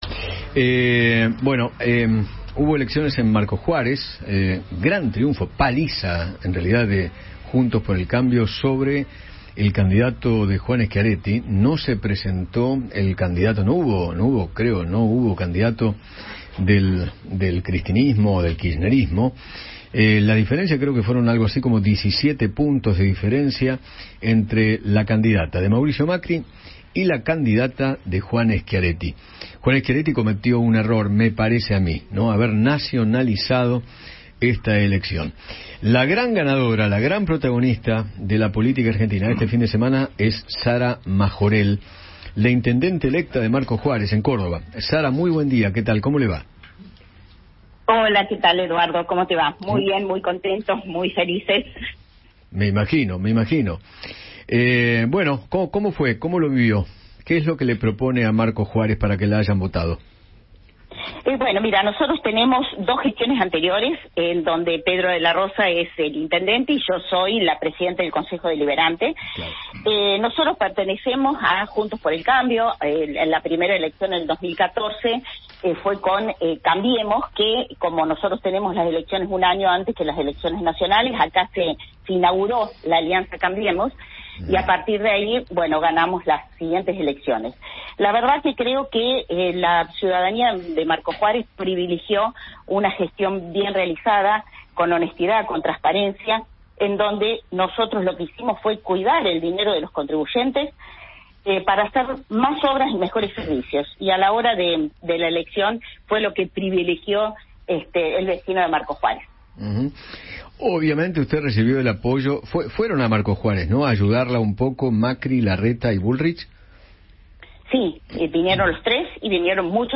Sara Majorel, intendenta electa de Marcos Juárez, perteneciente a Juntos por el Cambio, conversó con Eduardo Feinmann sobre la victoria que consiguió la coalición en un pueblo de la provincia de Córdoba, donde consideran que es la cuna de la alianza.